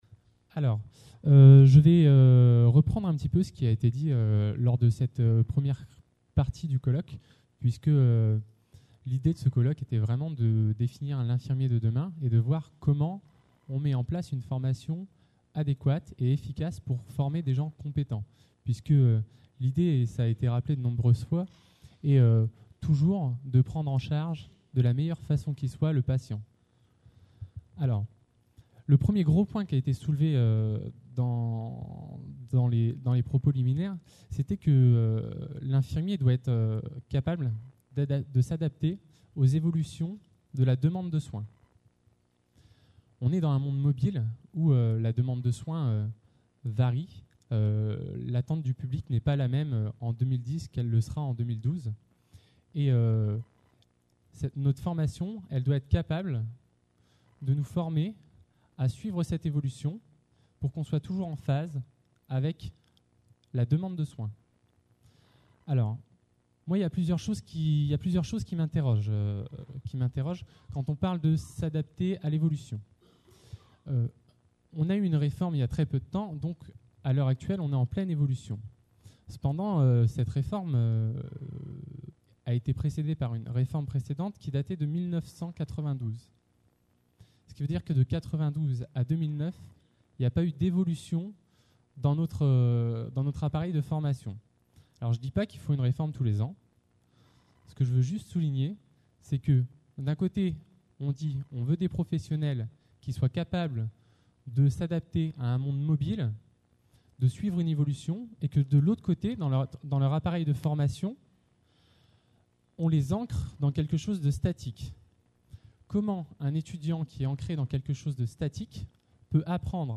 FNESI 2010 – Paris : Mise en place d’une formation - Table ronde | Canal U